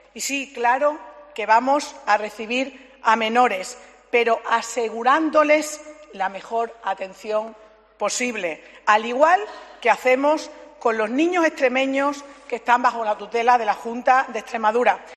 Sara Garcia, consejera de servicios sociales: "Vamos a asegurarles la mejor atención posible"